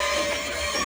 SERVO SE02.wav